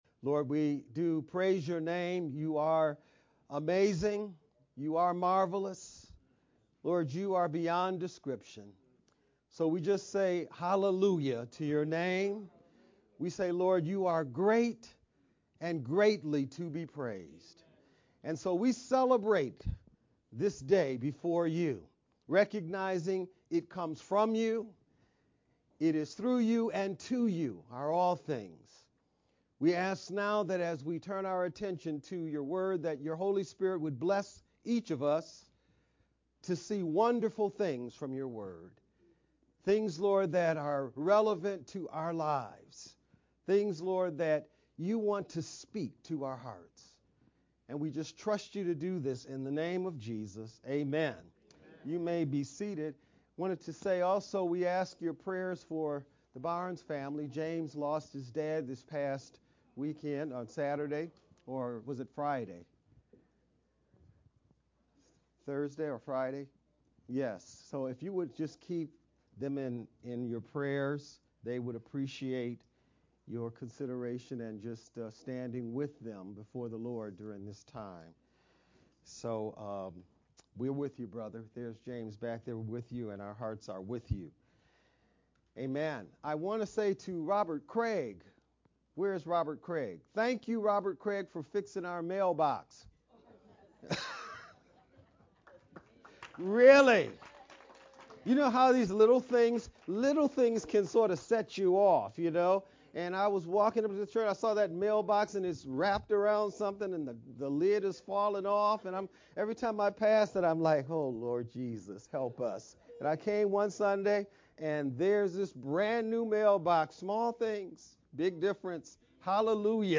Nov-3rd-VBCC-edited-sermon-only_Converted-CD.mp3